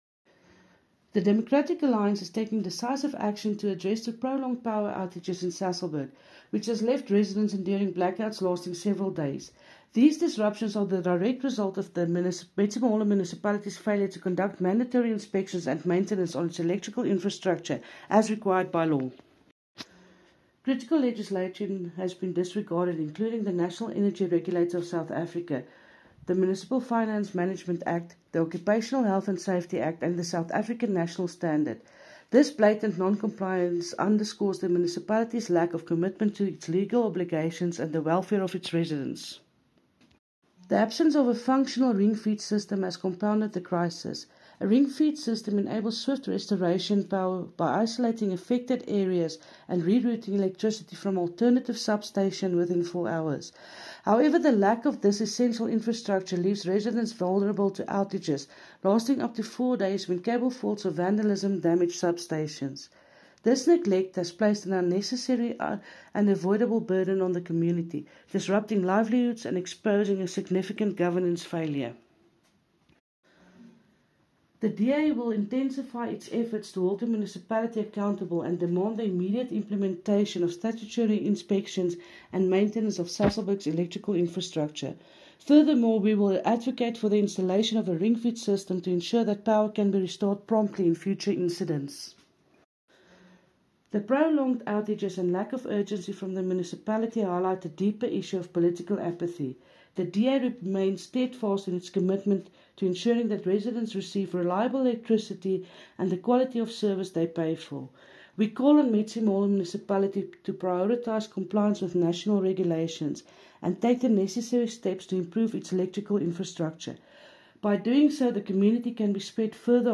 Afrikaans soundbites by Cllr Linda Day and